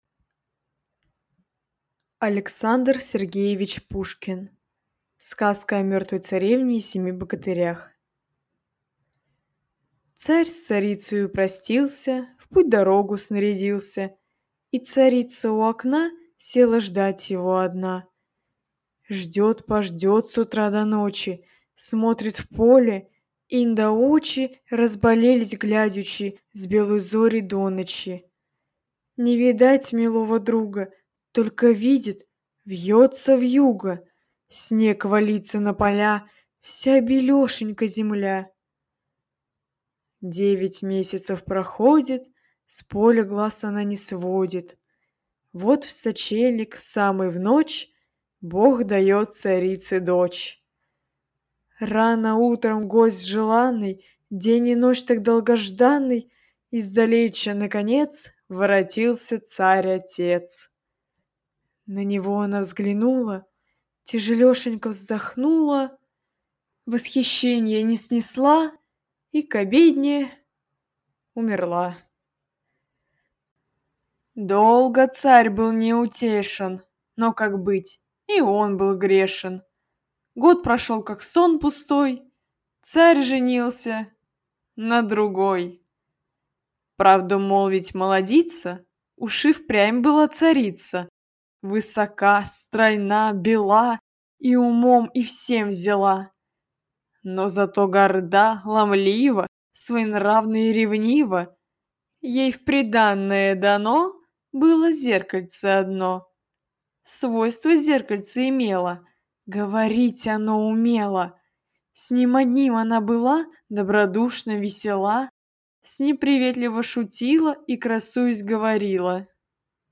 Аудиокнига Сказка о мертвой царевне и о семи богатырях | Библиотека аудиокниг